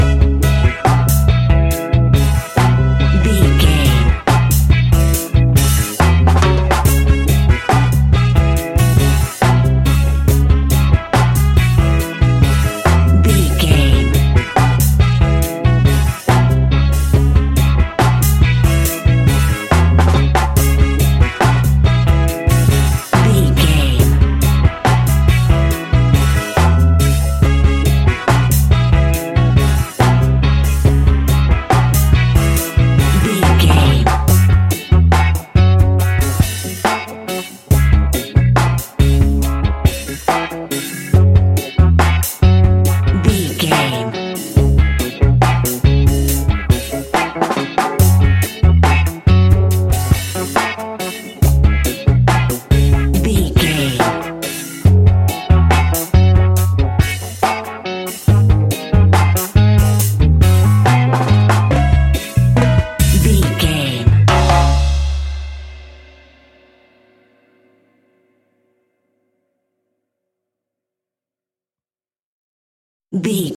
Classic reggae music with that skank bounce reggae feeling.
Aeolian/Minor
C#
instrumentals
laid back
chilled
off beat
drums
skank guitar
hammond organ
percussion
horns